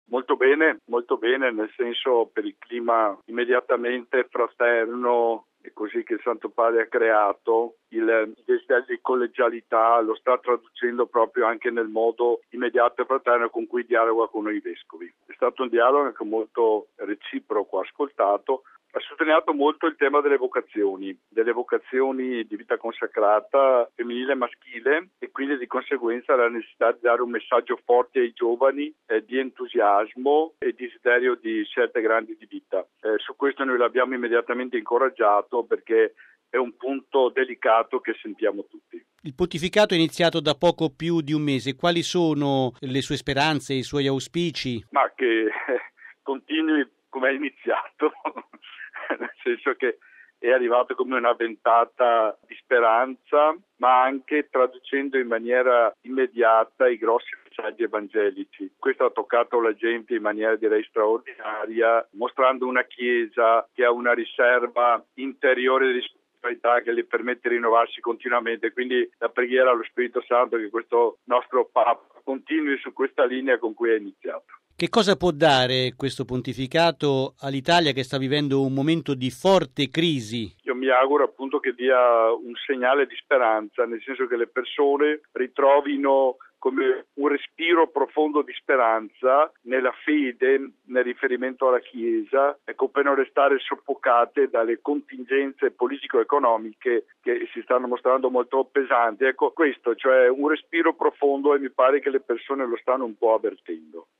◊   Il Papa ha ricevuto oggi un secondo gruppo di presuli della Conferenza episcopale del Triveneto, in visita “ad Limina”. Tra di loro c’era anche mons. Andrea Bruno Mazzocato, arcivescovo di Udine.